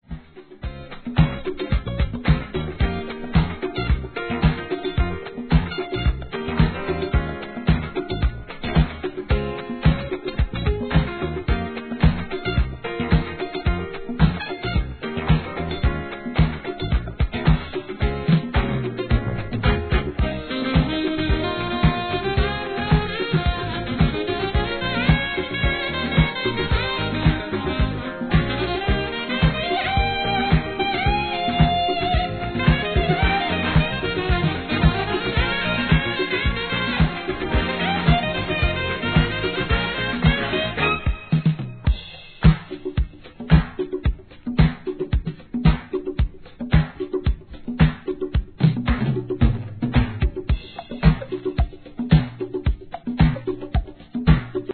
SOUL/FUNK